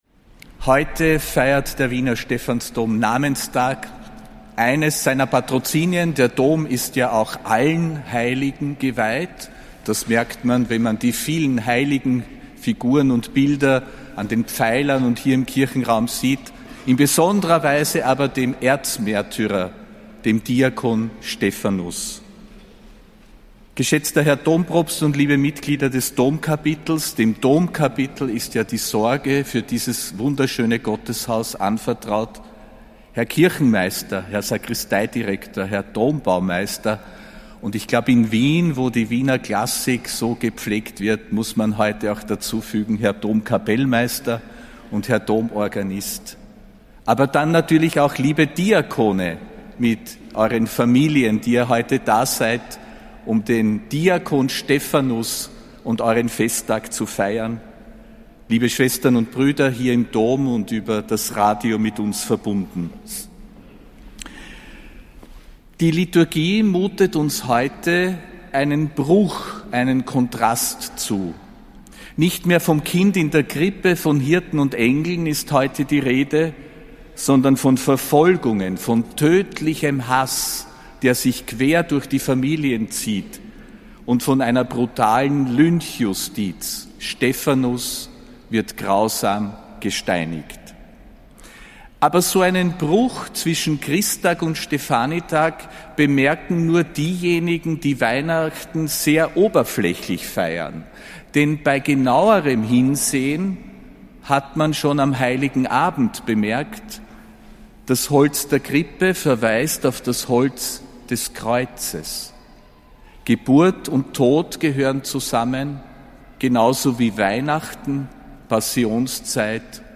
Predigt des Ernannten Erzbischofs Josef Grünwidl zum Stefanitag, am